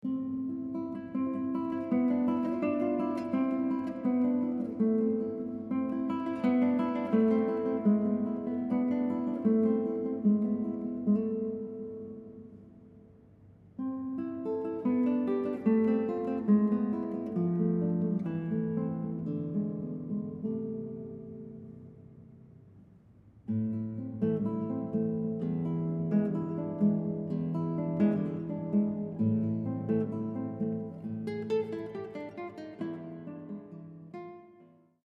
classical guitarist